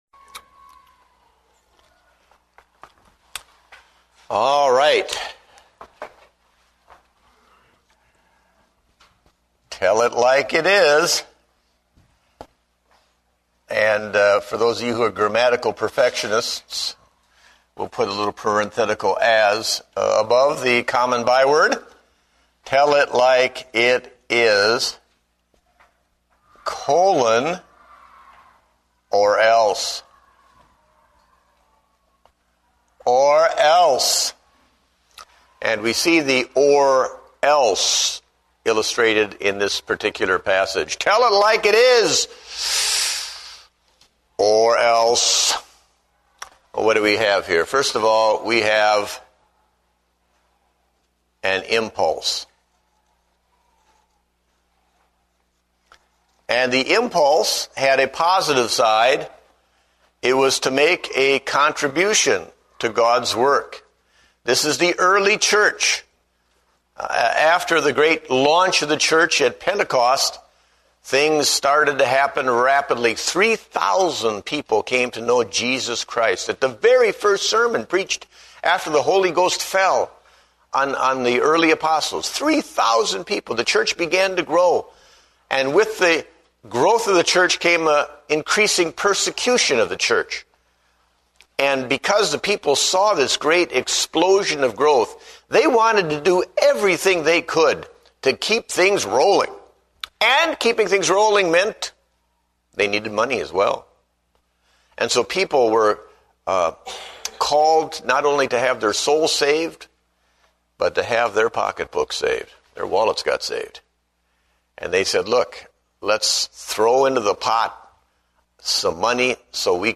Date: October 12, 2008 (Adult Sunday School)